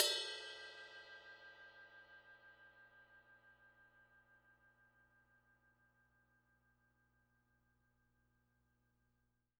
R_B Ride Bell 01 - Close.wav